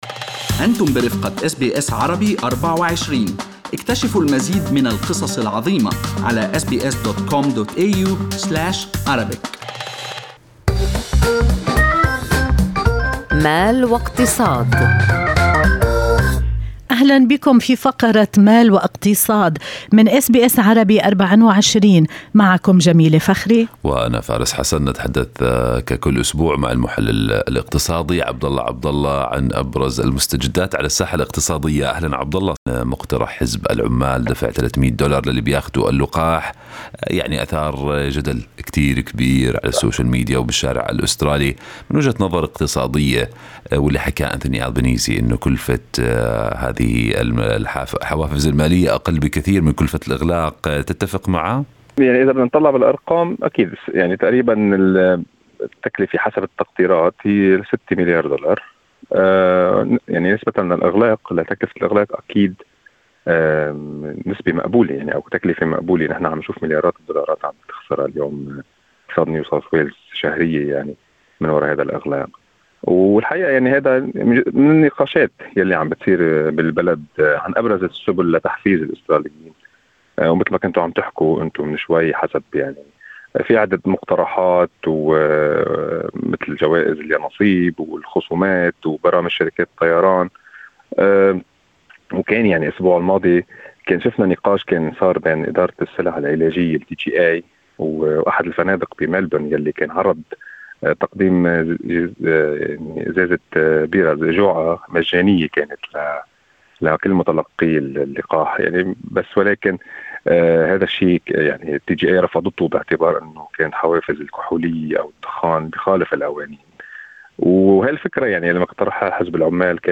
في لقاء مع أس بي أس عربي24